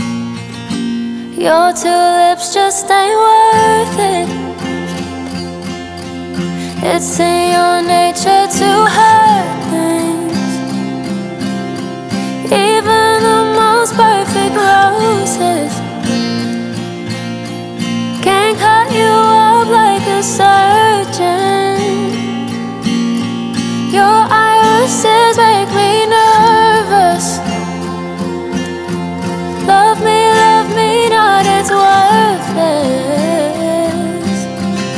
Pop Singer Songwriter
Жанр: Поп музыка